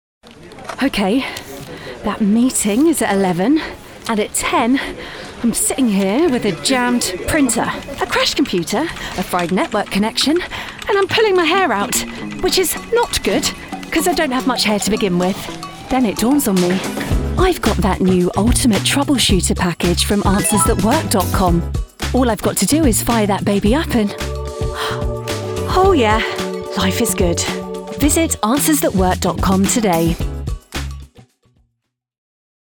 English (British)
Warm
Conversational
Friendly